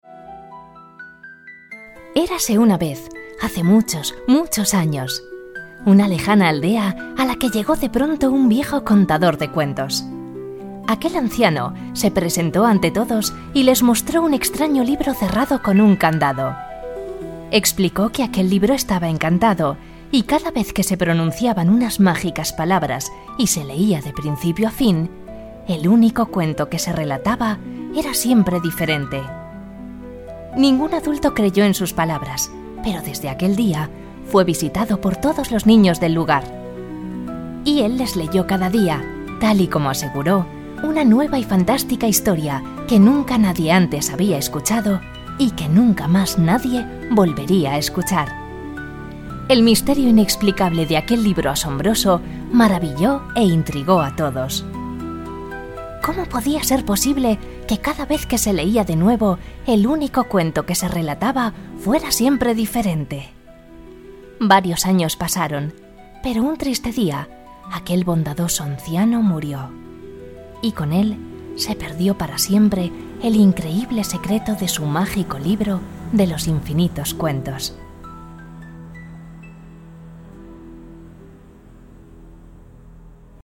El-Libro-de-Cuentos-Magico-Locucion.mp3